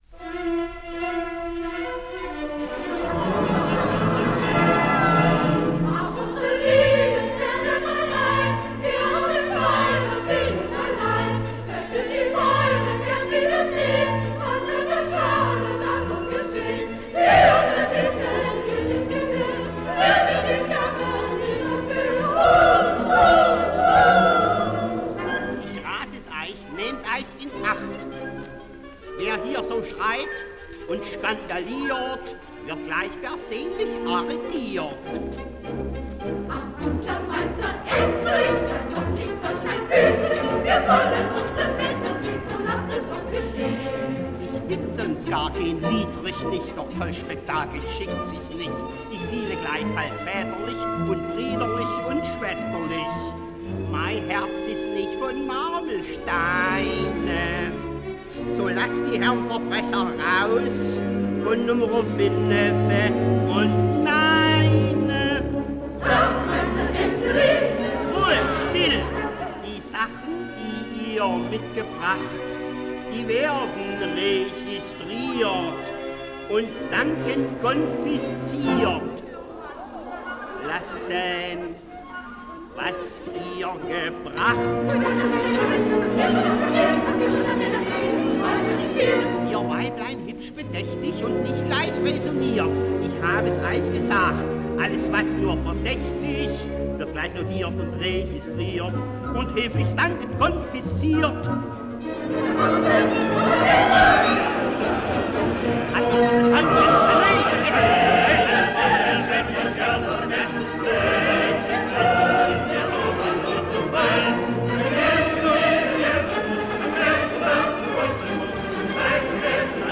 Recorded in 1930